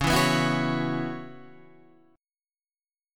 C# Minor 11th